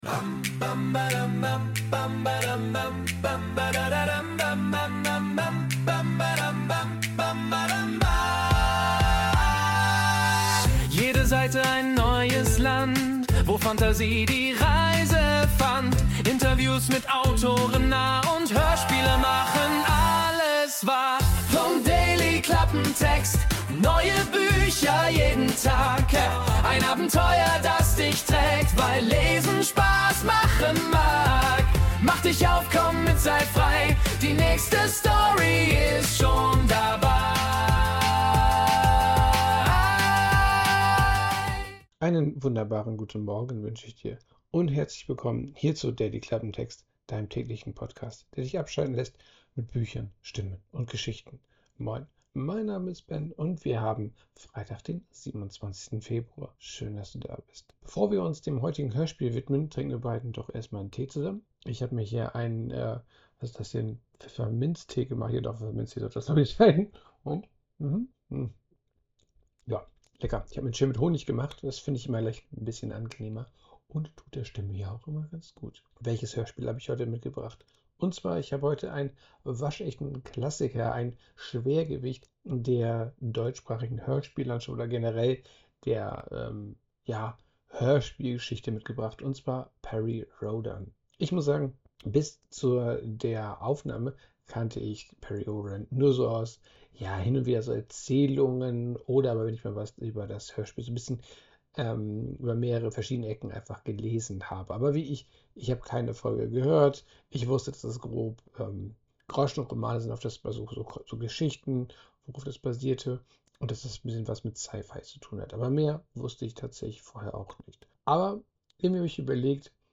Noch ein kurzer Hinweis in eigener Sache: Leider ist mir die Audioaufnahme der heutigen Folge nicht so gut gelungen, da mein Mikrofon zurzeit ein paar Probleme macht.
Bitte entschuldige die schlechte Qualität!